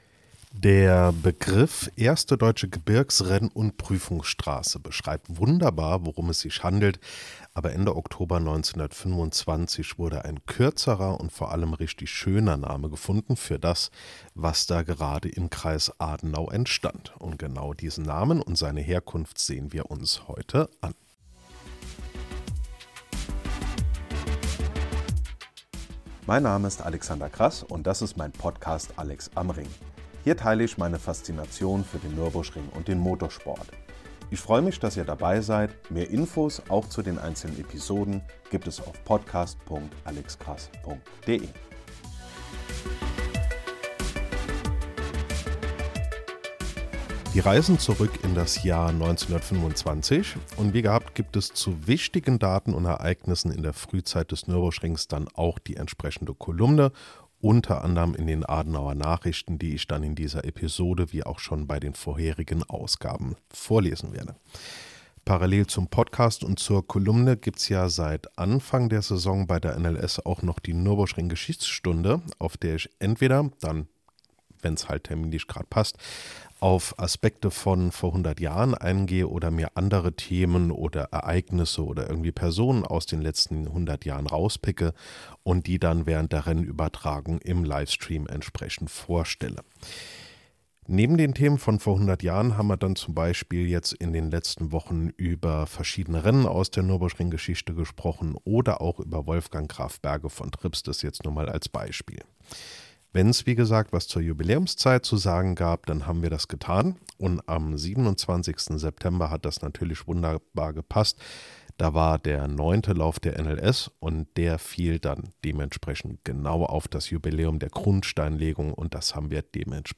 Am 30. Oktober 1925 wurde der Name "Nürburg-Ring" für das werdende Werk festgelegt. Grund genug zum Feiern, aber natürlich auch für eine Episode, in der ich unter anderem aus meiner Kolumne vorlese.